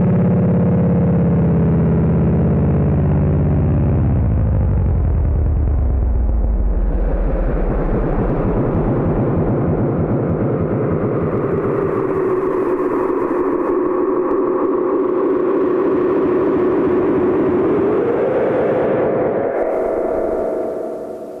描述：在一个穿越沼泽的平台上，在一条徒步旅行/骑自行车/慢跑的小路上，你可以听到昆虫和鸟儿在早晨醒来。 你还可以听到慢跑者经过，一种奇怪的鸣叫声狗的声音（自然学家请帮我鉴定一下），远处的卡车从沼泽地上的房子里捡起垃圾（自然和文明的有趣并列），狗的叫声，以及蚊子对话筒的俯冲轰炸。 麦克风面向沼泽对面的房屋。 这段录音是2007年8月21日上午在美国弗吉尼亚州弗吉尼亚海滩的First Landing州立公园用Zoom H4录制的。 轻微的后期制作工作在Peak.
标签： 鸟类 沼泽 现场录音 请先登陆状态园 昆虫
声道立体声